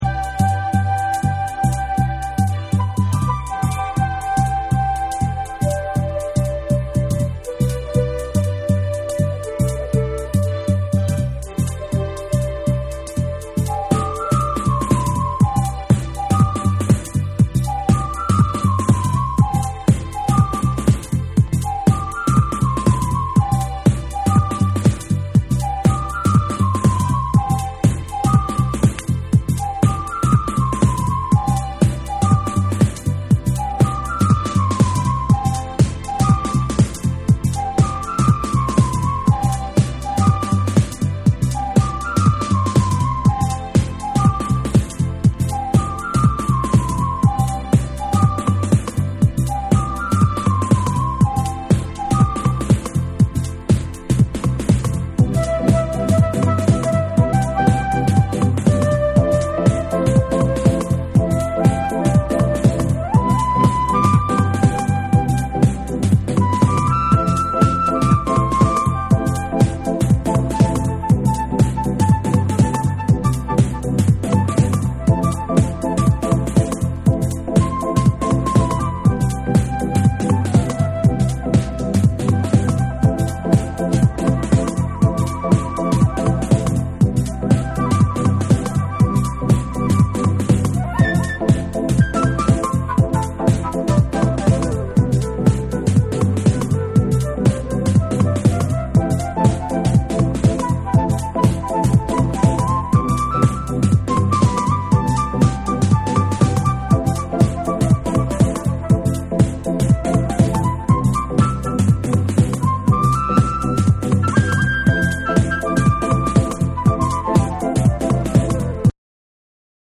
スイングしまくる4/4トラックとベースライン
TECHNO & HOUSE